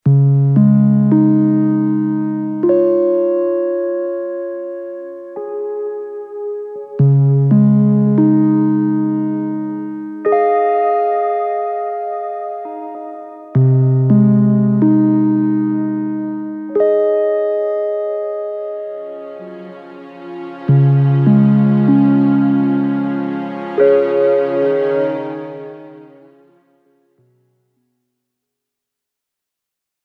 På stranden – Ljudbok – Laddas ner